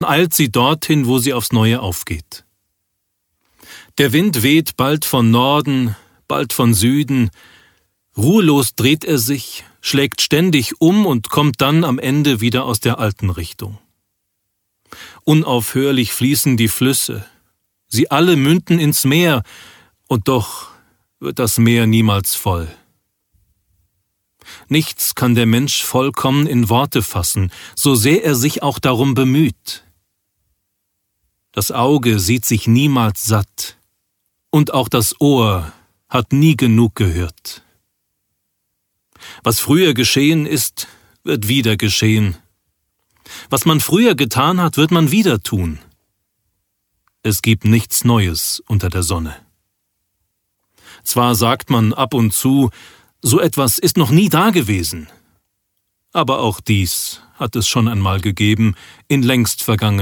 Hörbücher